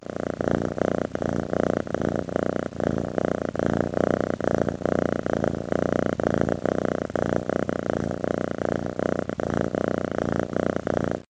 кошка